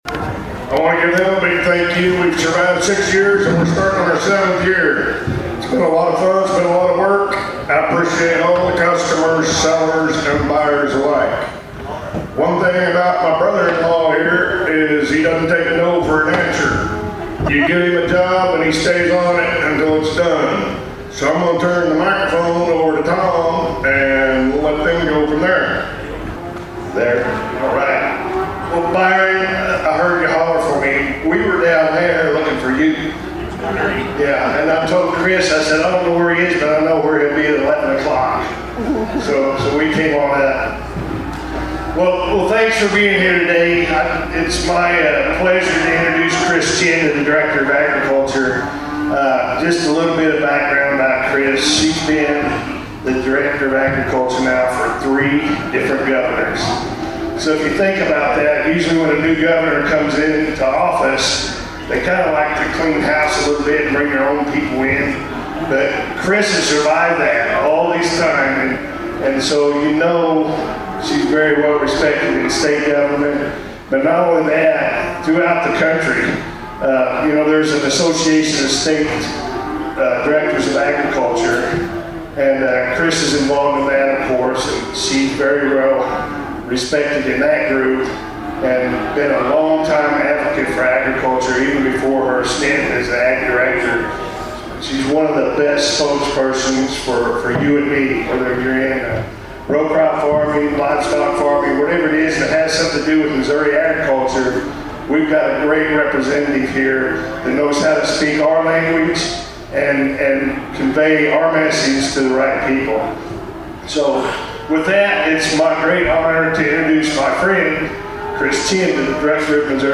Director Chinn, along with other state officials, was on hand to make a special announcement for Missouri’s agriculture community.